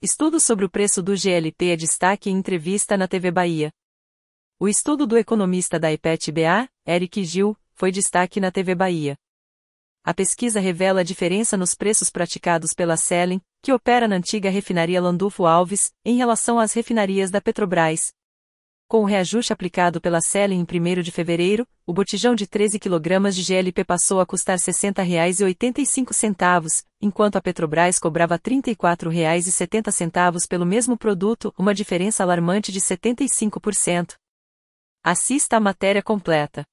Estudo sobre o preço do GLP é destaque em entrevista na TV Bahia